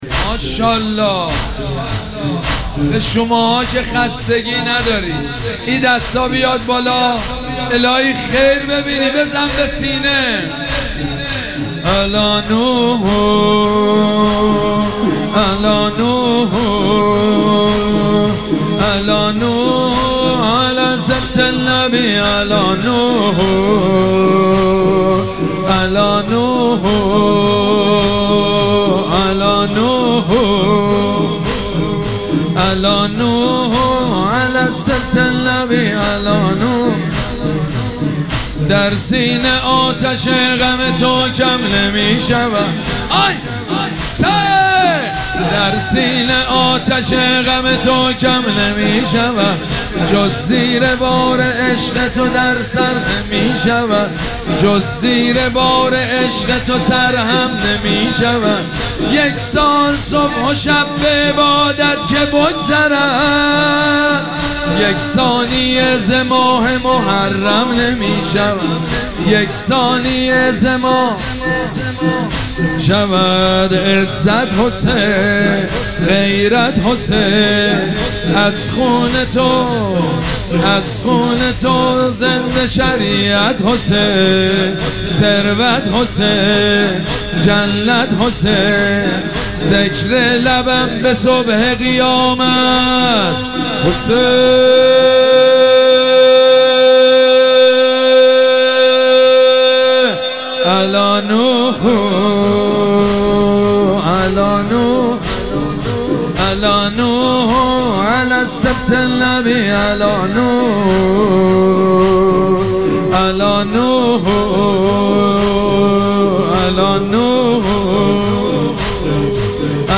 شب دوم مراسم عزاداری اربعین حسینی ۱۴۴۷
مداح
حاج سید مجید بنی فاطمه